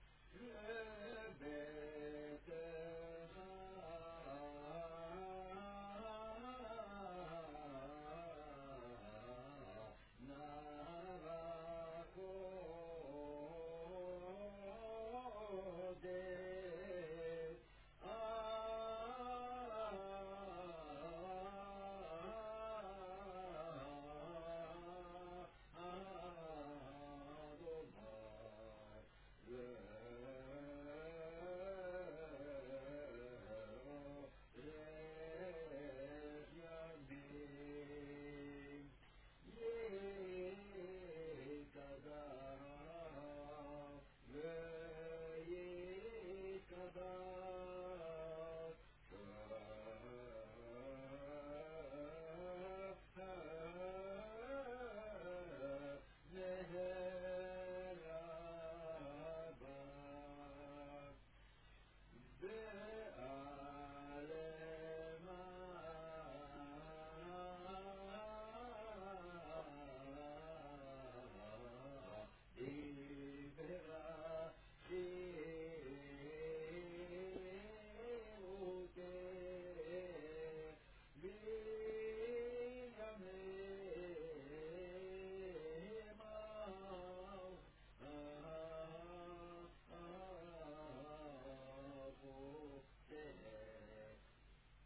The middle verse is read by the Chazzan